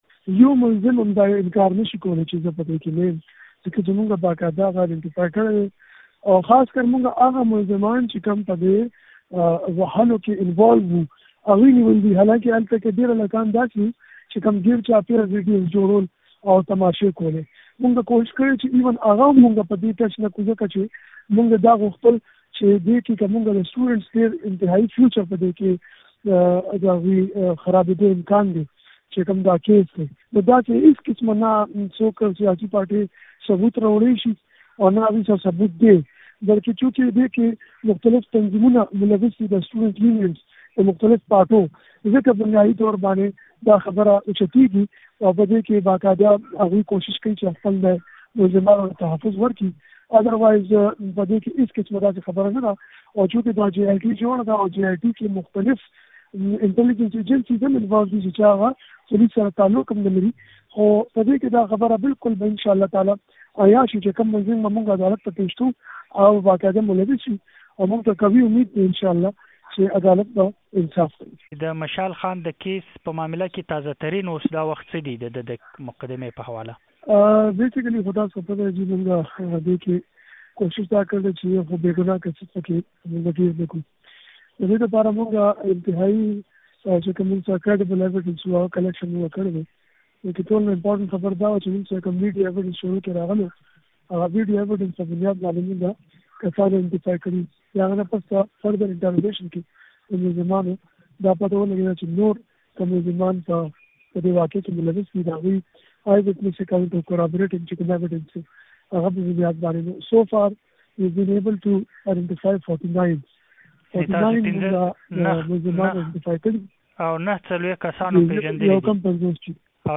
د خېبرپښتونخوا مردان پوليسو مشر ويلي، د عبدالولي خان پوهنتون د زدکوونکي مشال خان د وژنې په مقدمه کې د مختلفو سياسي ګوندونو سره تړاولرونکي تنظيمونه لاس لري نو ځکه هغوي هڅه کوي چې نيول شوي تورن کسان و ژغوري. ډاکټر ميا سعيد د شنبې په ورځ مشال راډيو ته د ځانګړې مرکې پر مهال وويل چې پوليسو د مشال خان د وژنې په تور ۴۹ کسان پېږندلي دي چې پکې يې ۴۷ نيولي او پاتې دوه قبايلي سيمو ته تښتېدلي دي.